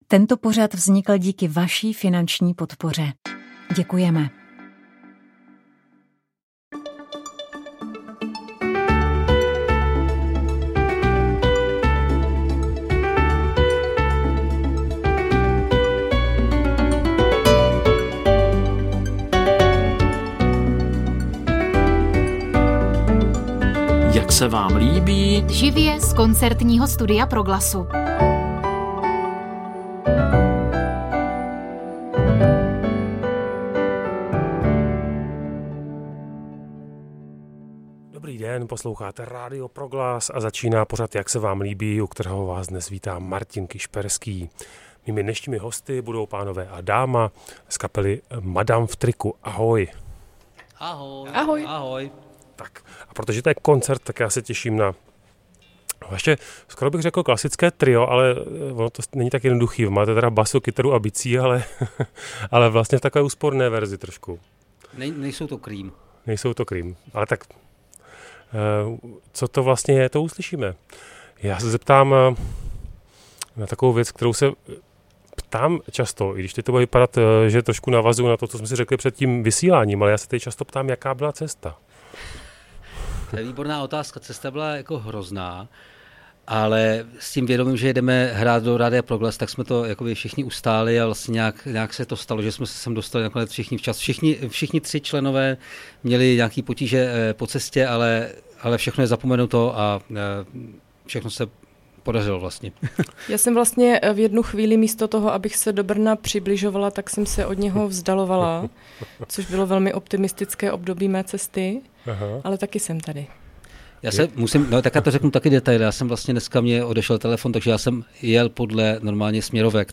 Rozhovor s indickým hráčem na perkuse Trilokem Gurtu